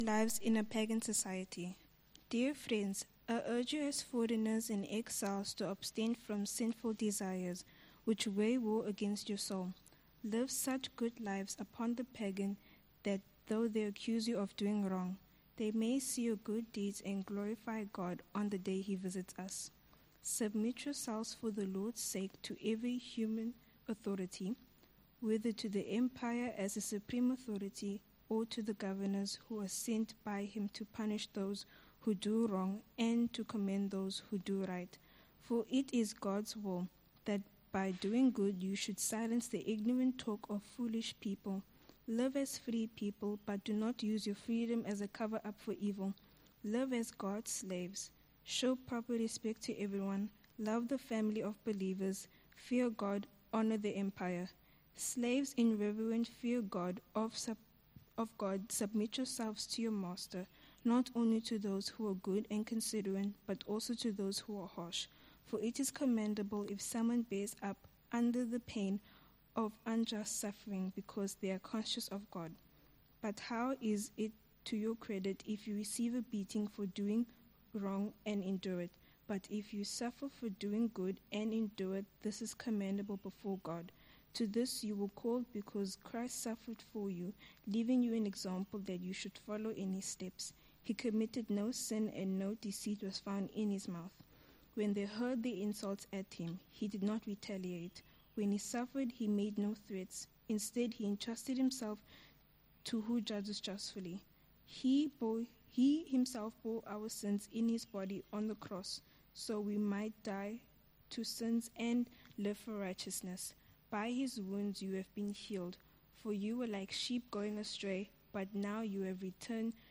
1 Peter Passage: 1 Peter 2: 11-25 Service Type: Morning Service « Belonging